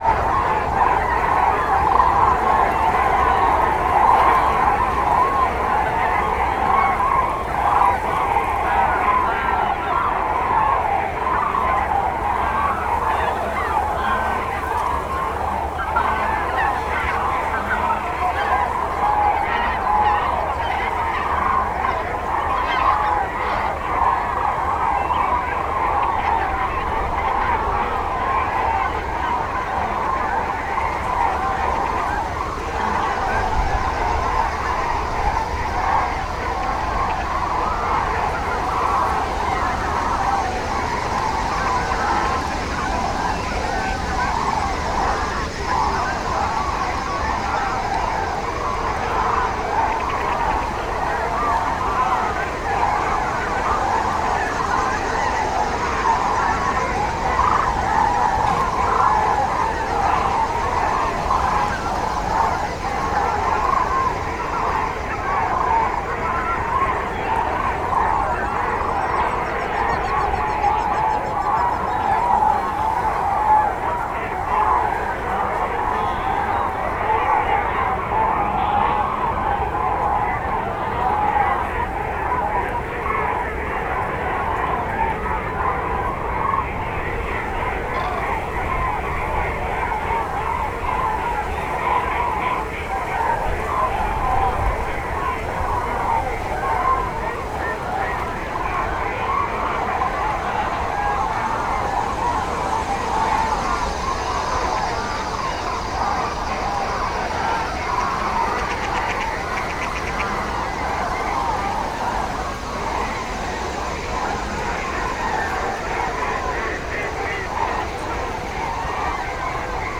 tobbfelszallorececsapat_fr2akg_hortobagyicsatorna02.44.WAV